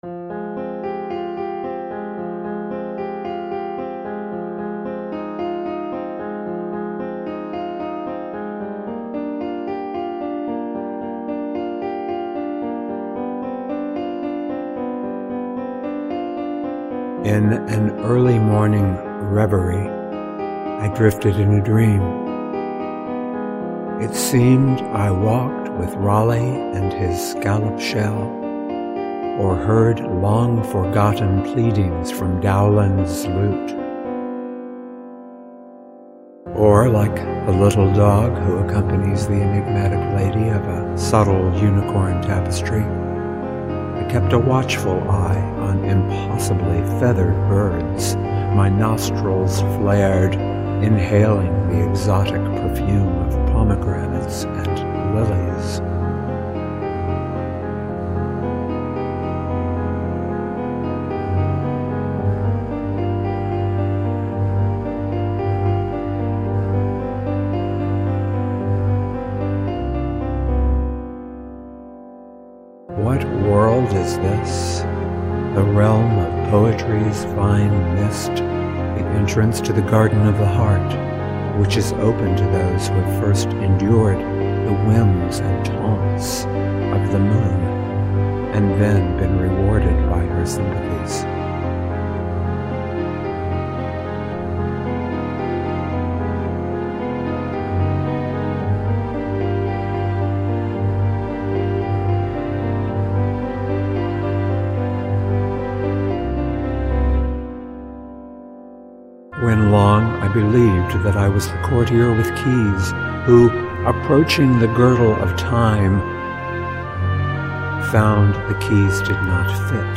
Genre Laidback